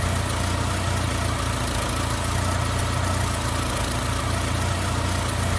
engine sfx
car_sfx.wav